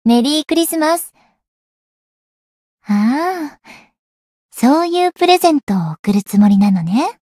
灵魂潮汐-星见亚砂-圣诞节（相伴语音）.ogg